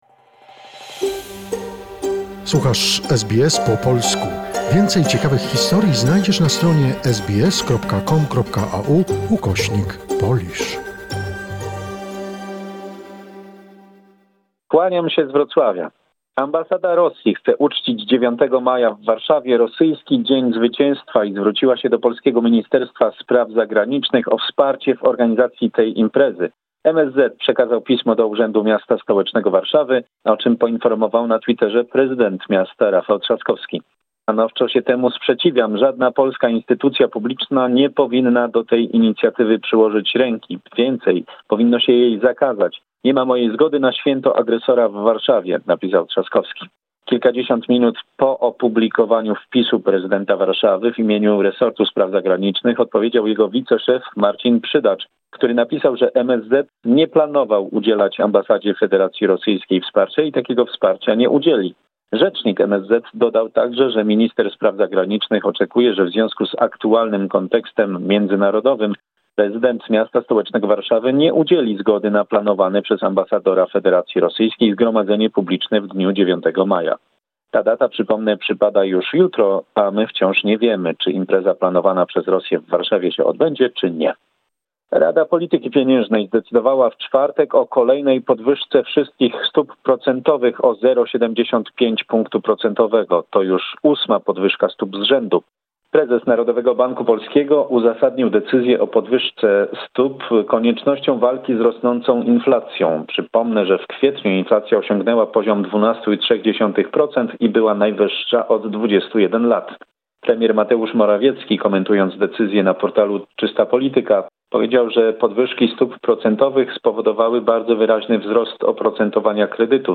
Summary of the important events in Poland. Report